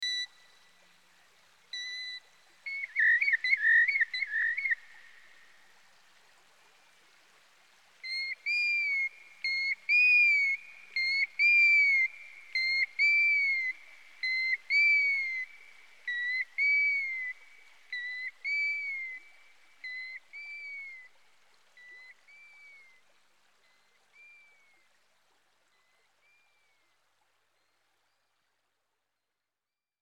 Springar
Norwegian folk music